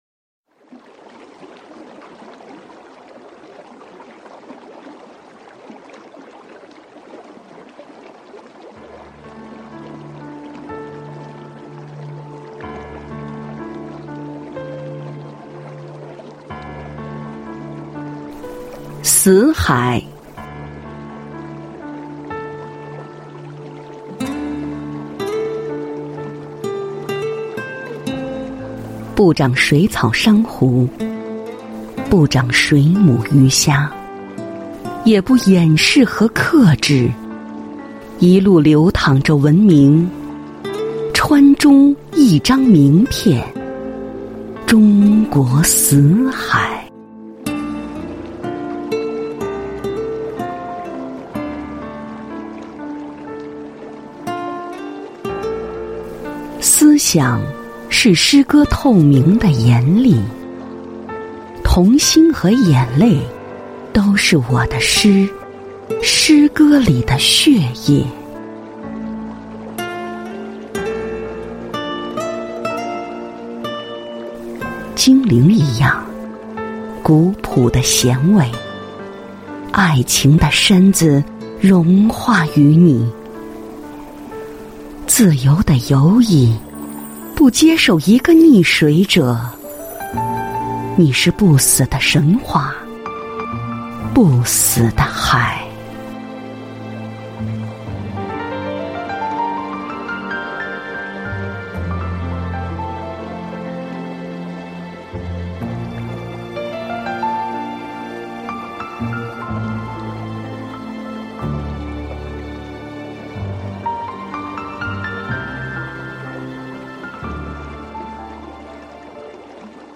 誦讀